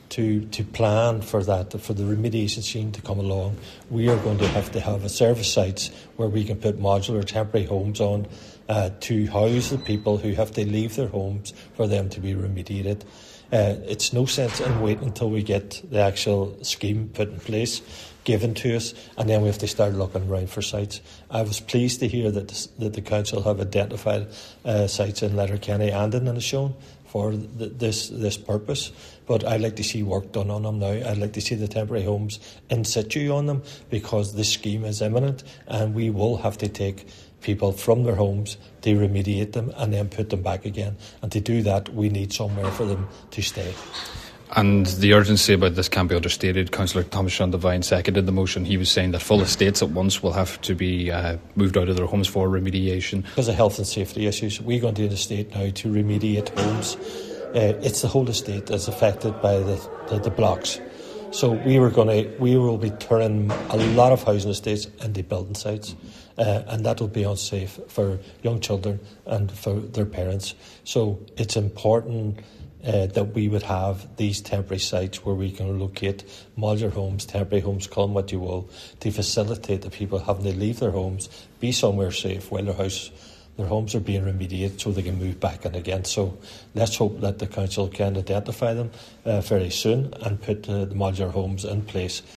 Cllr McMonagle says this has to happen as soon as possible as a lot of housing estates will become building sites: